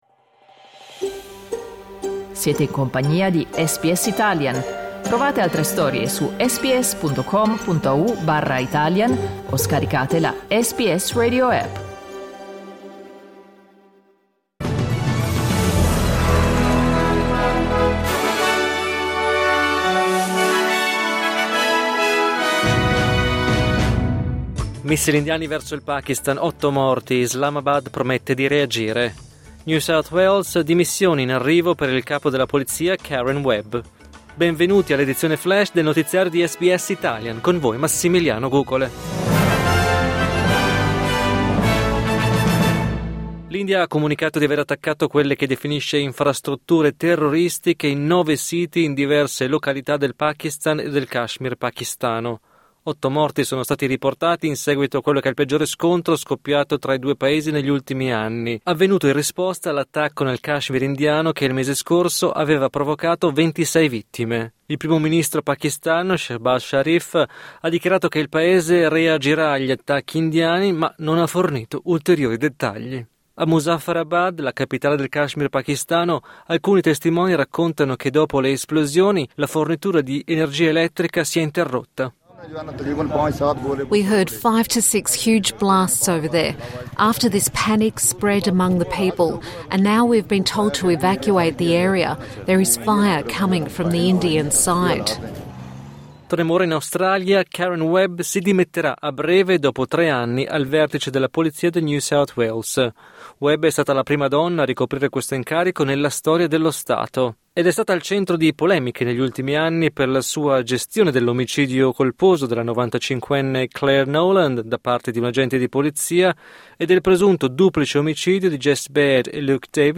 News flash mercoledì 7 maggio 2025
L’aggiornamento delle notizie di SBS Italian.